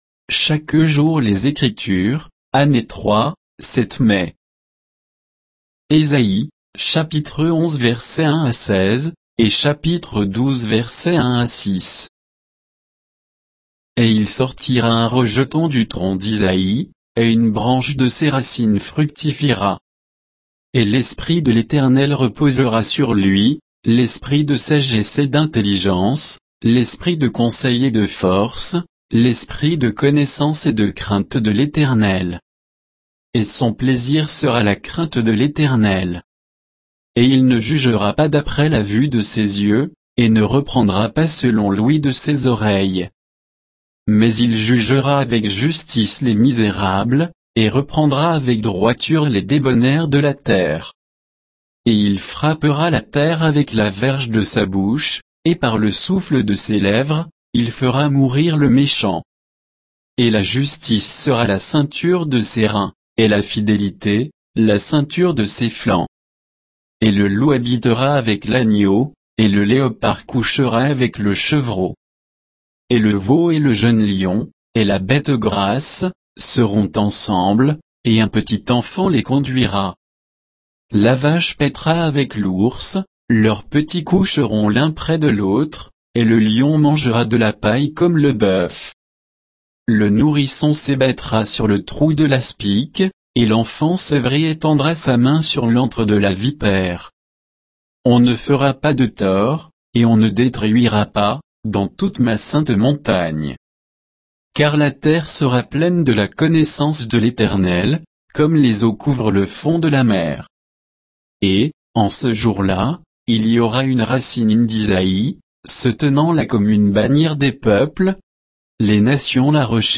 Méditation quoditienne de Chaque jour les Écritures sur Ésaïe 11